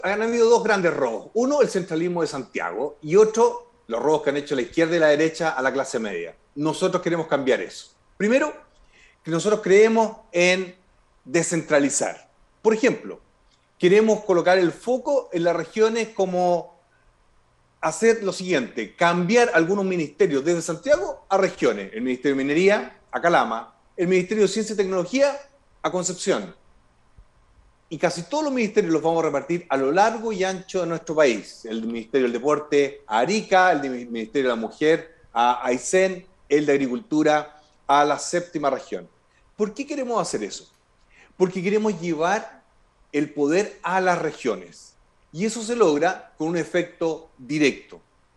Con entrevista a Franco Parisi finalizó Presidenciales en Medios UdeC - Radio UdeC